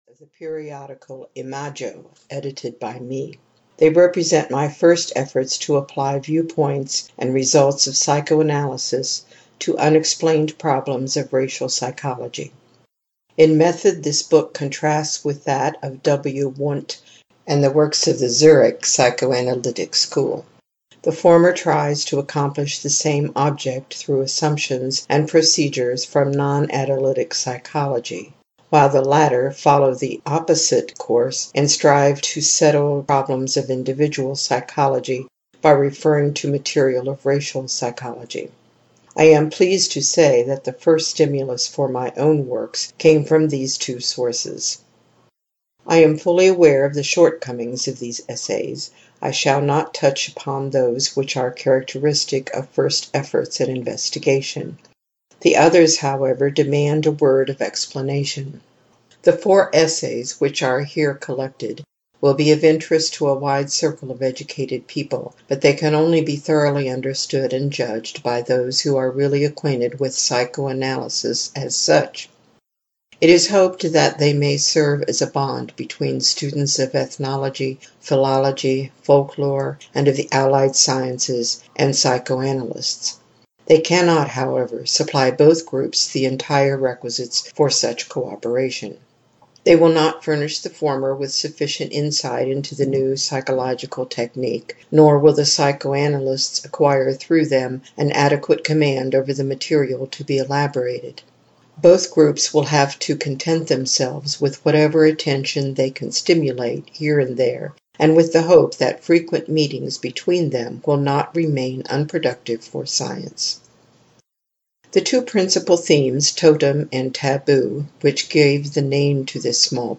Totem and Taboo (EN) audiokniha
Ukázka z knihy